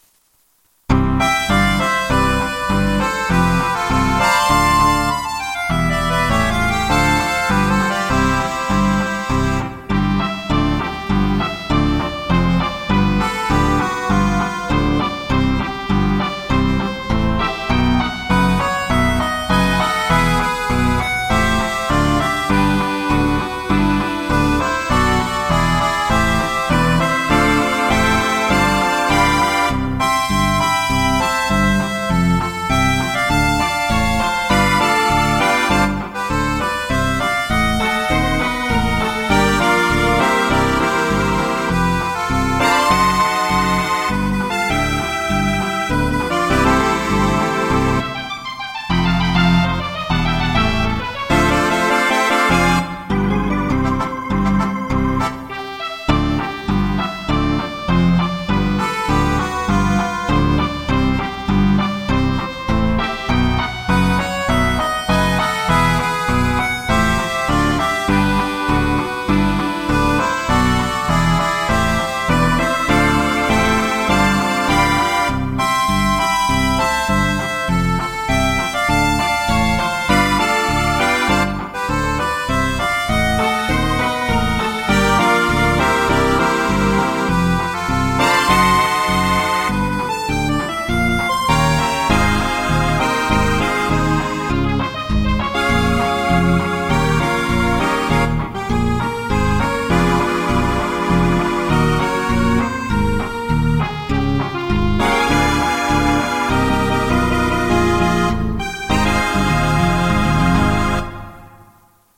для аккордеона и баяна
Фокстрот